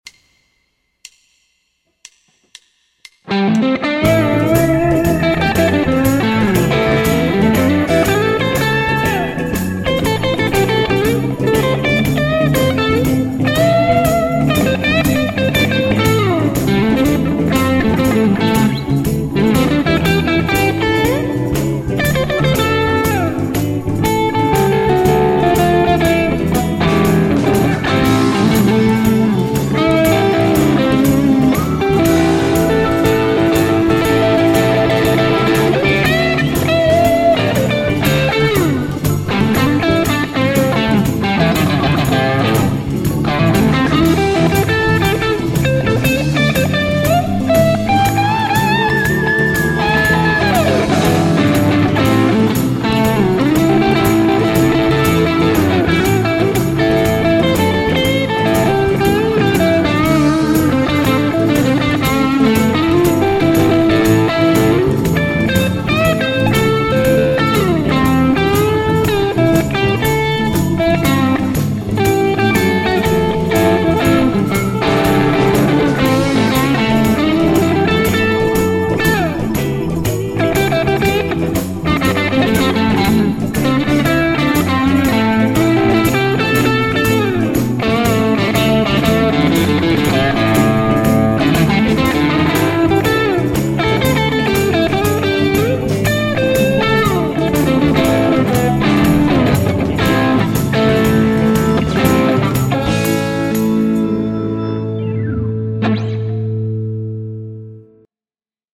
- soita soolosi annetun taustan päälle
- taustan tulee olla sointukierroltaan blues (I-IV-V)- sointuja ja tyyliä soveltaen.
Hyvä soundi ja hyvä meininki myös tässä.
Kuulostaa kovasti straton kaulamikiltä, mutta mistäs näitä tietää.
Ei emmi, ei pyydä vaan painaa päälle mallikkaasti.